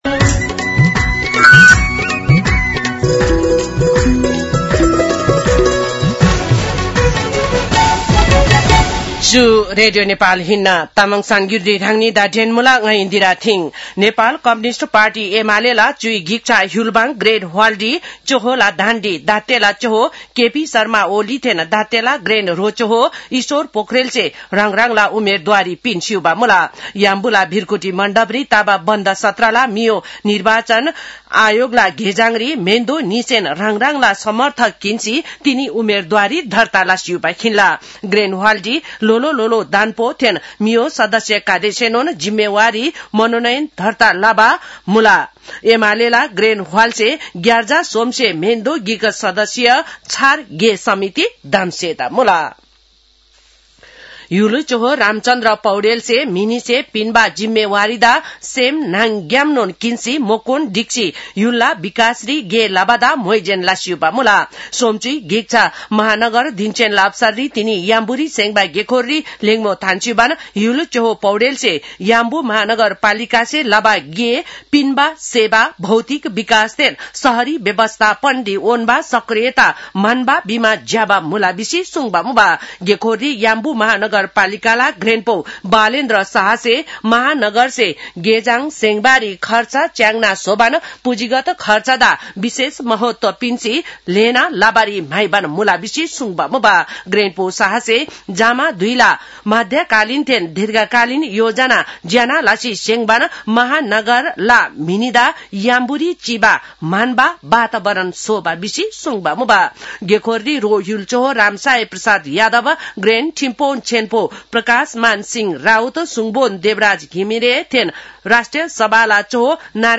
An online outlet of Nepal's national radio broadcaster
तामाङ भाषाको समाचार : २९ मंसिर , २०८२